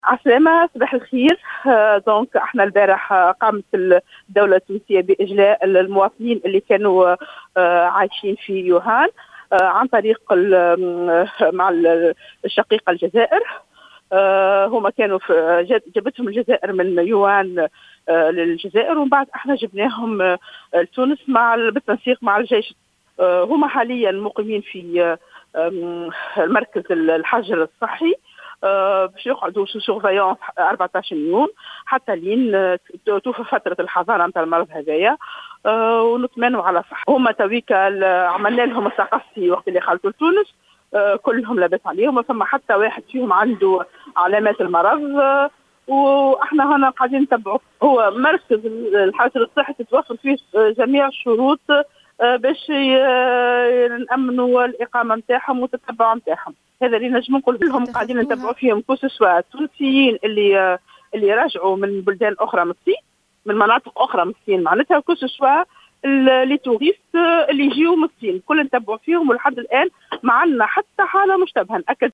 وأفادت في تصريح لـ "الجوهرة اف أم" بانهم يقيمون حاليا في مركز الحجر الصحي لمدة 14 يوما كما تتوفر فيه جميع الشروط لتأمين إقامتهم ومتابعتهم طبيا، في اجراء وقائي، مؤكدة سلامتهم حاليا من فيروس كورونا.